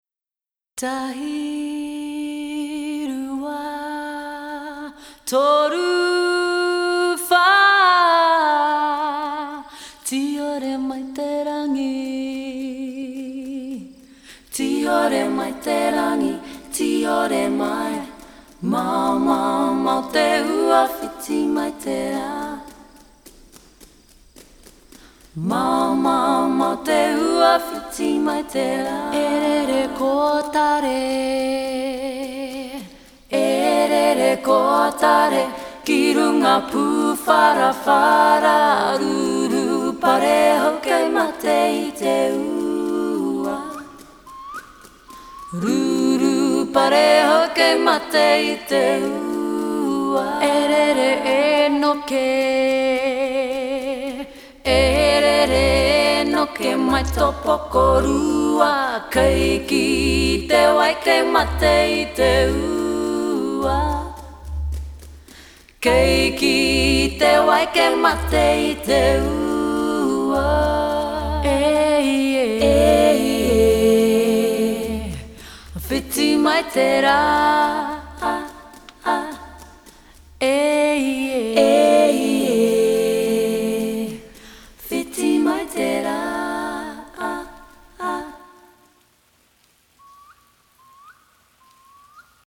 Genre: Hip Hop, Jazz, Rock, Funk